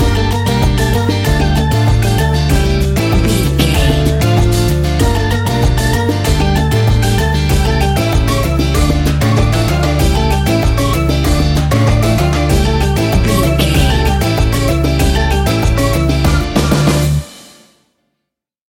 Aeolian/Minor
steelpan
drums
bass
brass
guitar